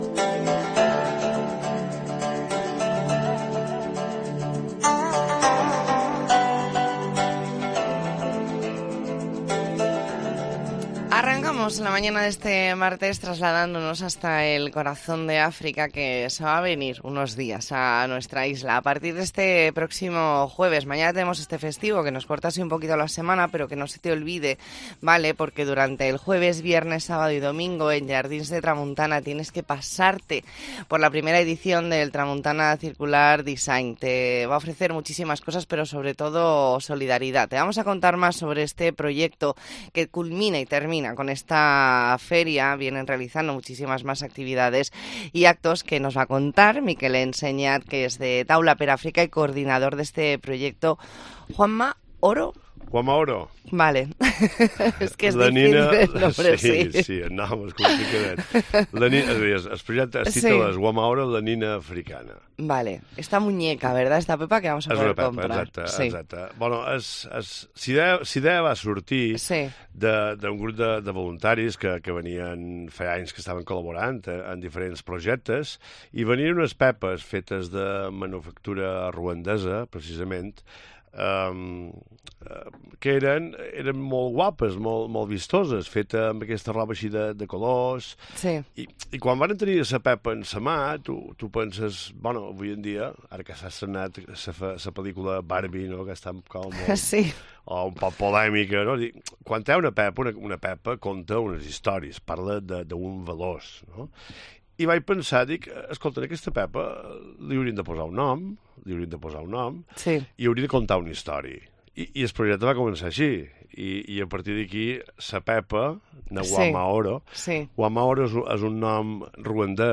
Entrevista en La Mañana en COPE Más Mallorca, martes 31 de octubre de 2023.